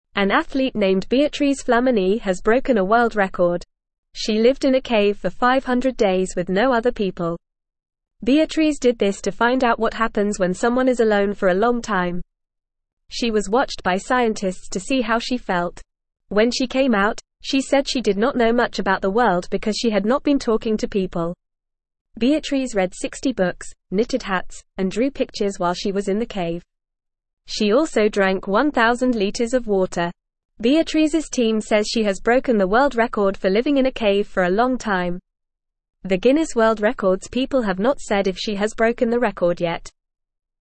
English-Newsroom-Beginner-FAST-Reading-Woman-Lives-in-Cave-for-500-Days.mp3